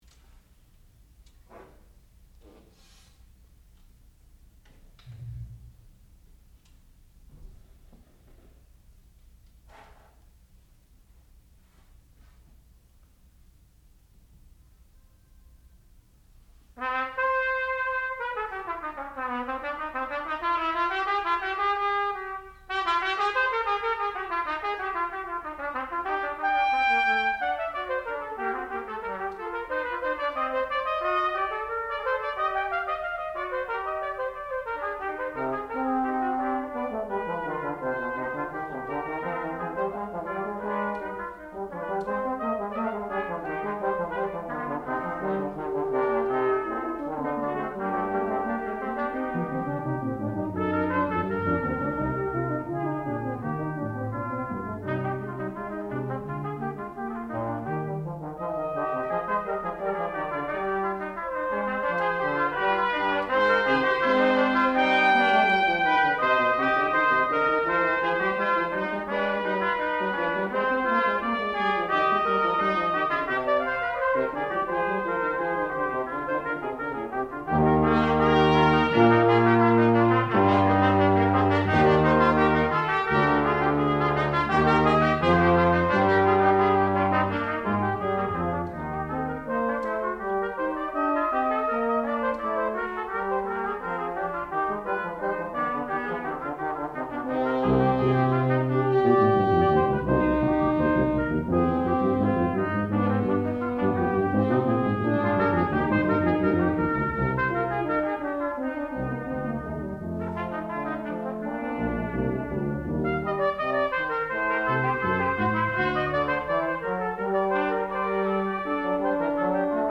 sound recording-musical
classical music
trombone
trumpet
horn